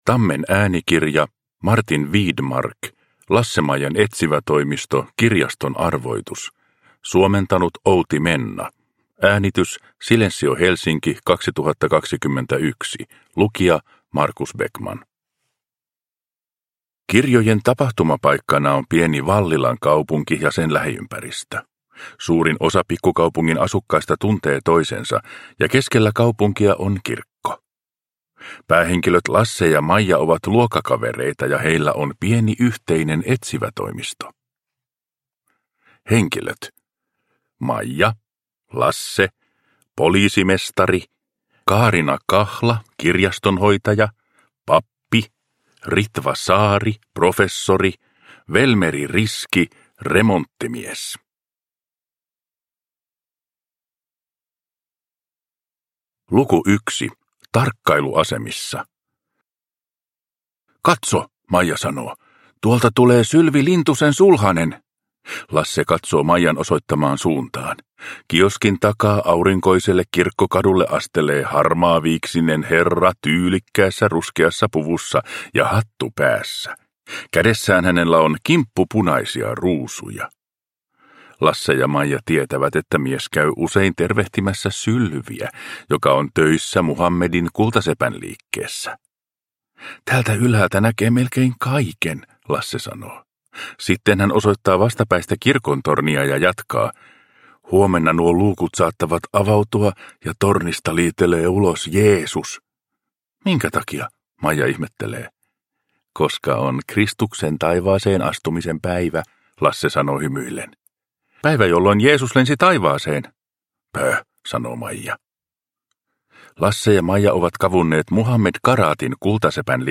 Kirjaston arvoitus. Lasse-Maijan etsivätoimisto – Ljudbok – Laddas ner